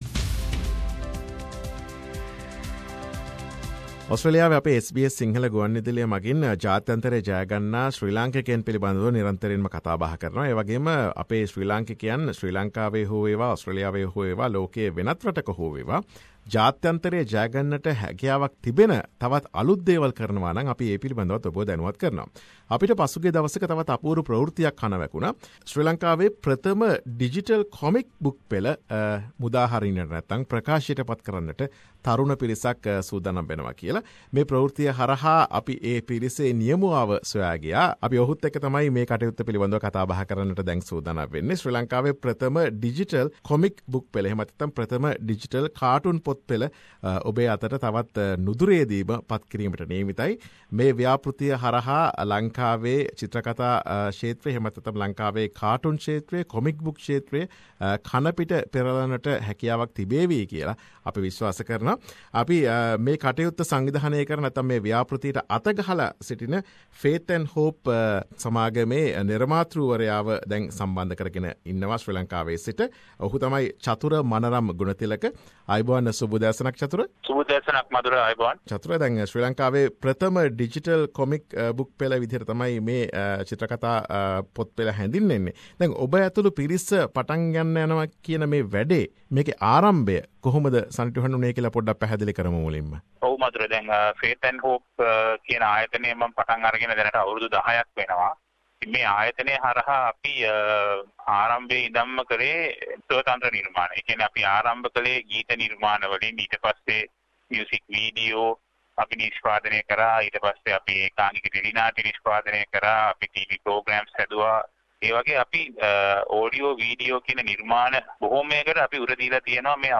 SBS සිංහල ගුවන් විදුලිය සිදු කළ සාකච්ඡාවකි මේ.